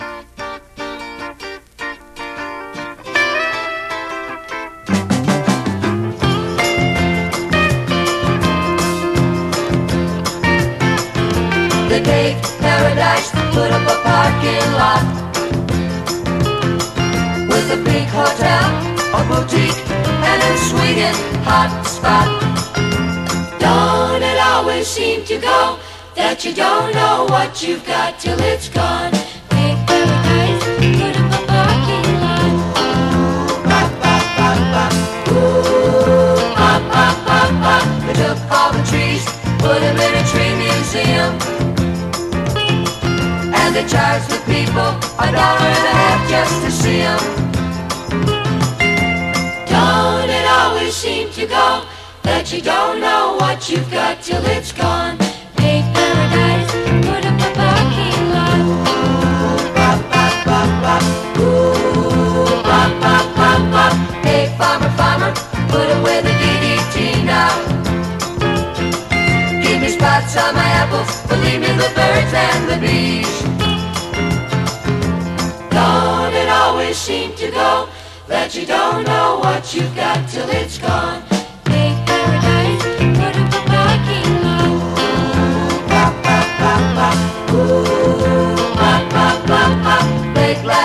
スレあるため試聴でご確認ください